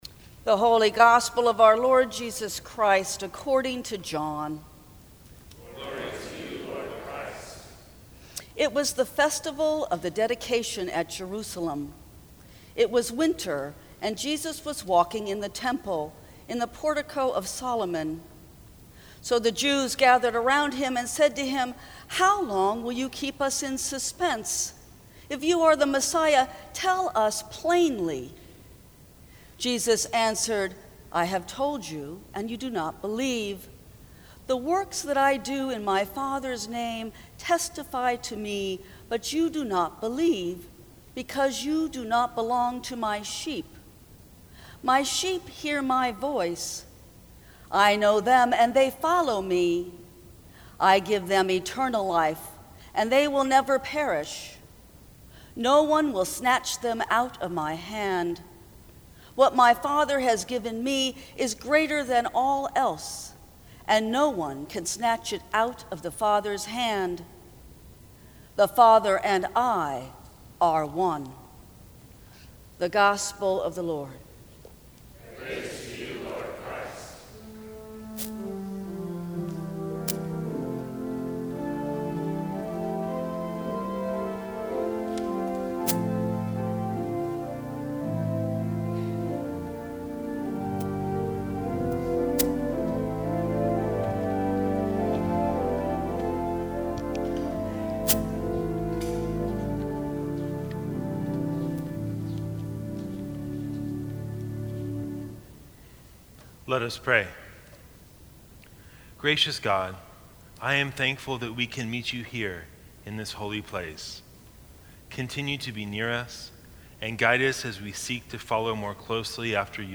Sermons from St. Cross Episcopal Church In God’s Gentle Hands May 13 2019 | 00:10:45 1x 00:00 / 00:10:45 Subscribe Share Apple Podcasts Spotify Overcast RSS Feed Share Link Embed